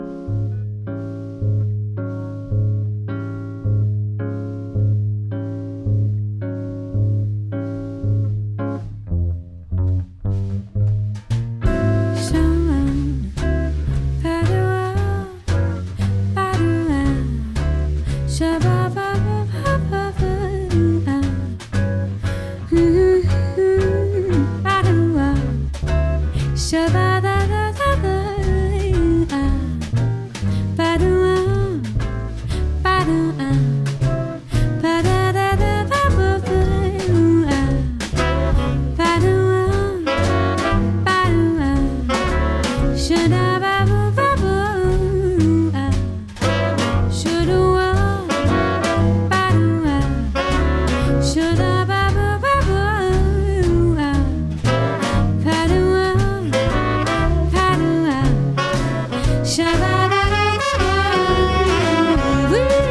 violon, chant
trompette
saxophones ténor et soprano
accordéon
guitare
contrebasse
batterie.